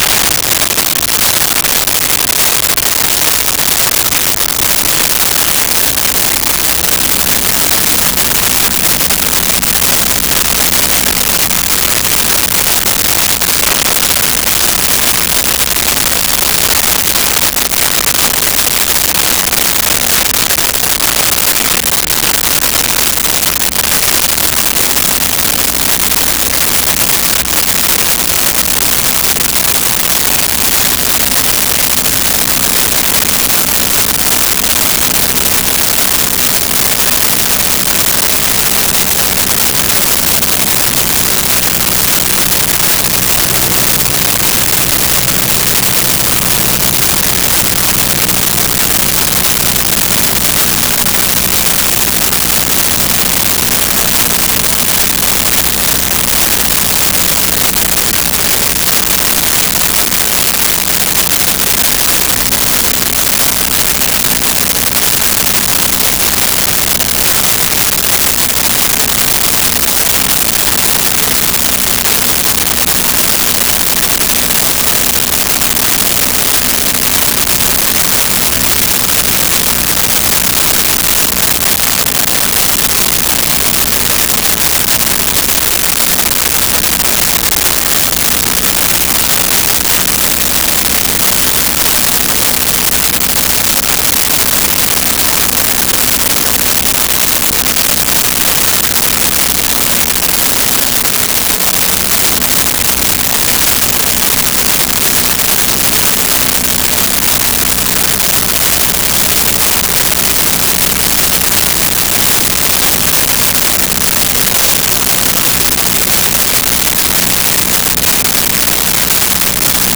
Prop Plane High Steady
Prop Plane High Steady.wav